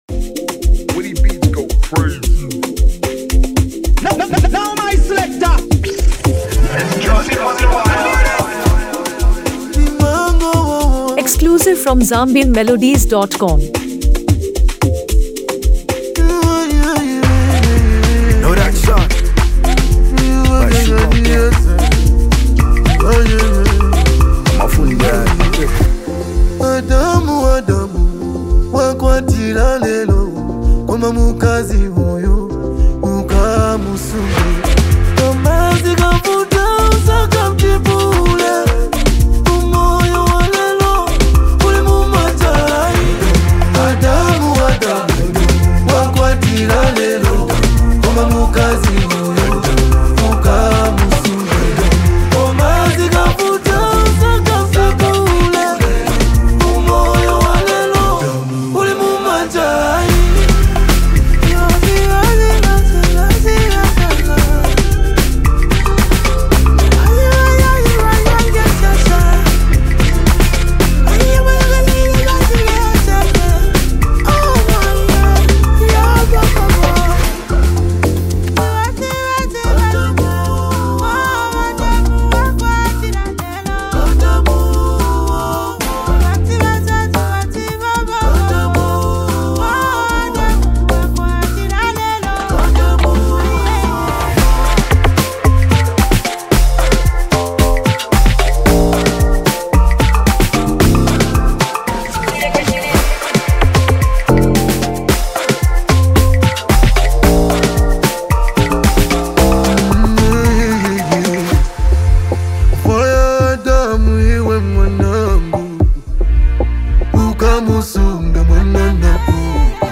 A Powerful Hip-Hop Collaboration in Mp3
conscious Zambian hip-hop